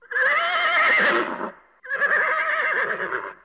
دانلود صدای اسب برای کودکان از ساعد نیوز با لینک مستقیم و کیفیت بالا
جلوه های صوتی
برچسب: دانلود آهنگ های افکت صوتی انسان و موجودات زنده